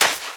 STEPS Sand, Run 09.wav